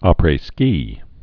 prā-skē, ăprā-)